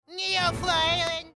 PLAY Doodlebob "Wrench" Scream
doodlebob-wrench-scream.mp3